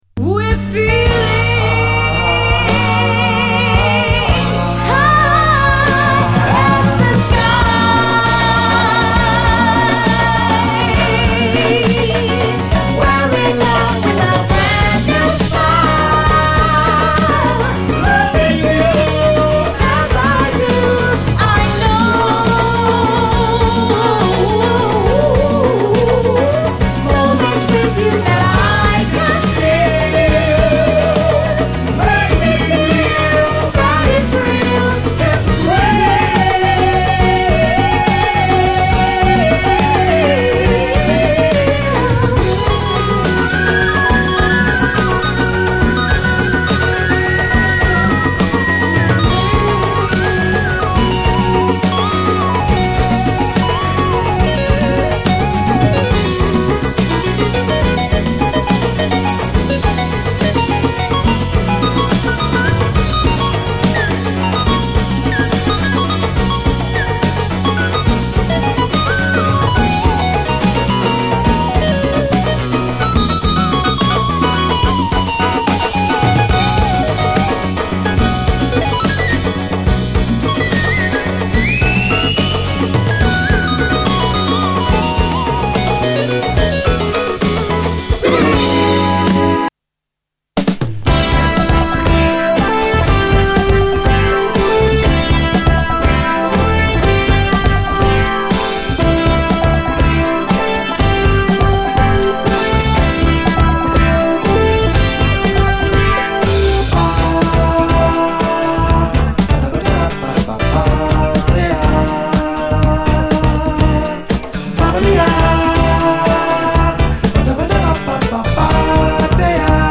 オブスキュアなキラー・トラック！特に、後半にかけてありったけの高揚感で畳み掛ける美しいピアノが超最高です！！